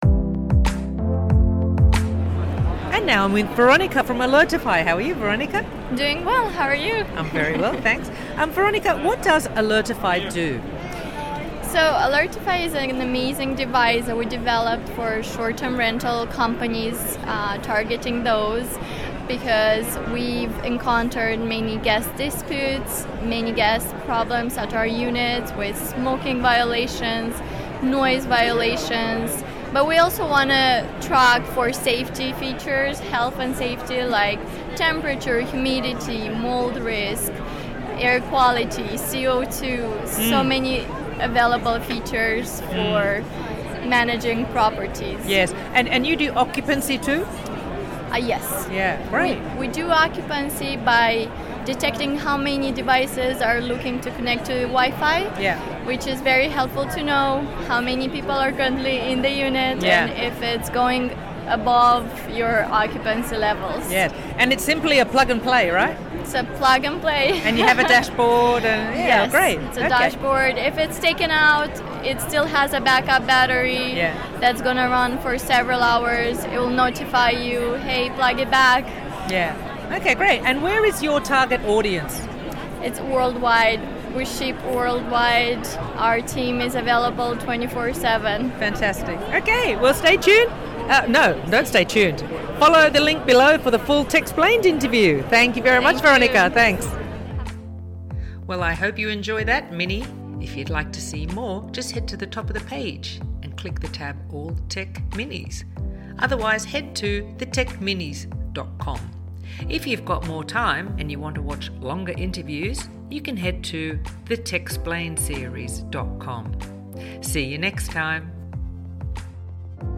Purchase your device with 5% off using the code: TECH5 😀 Alertify has had a Techsplained interview.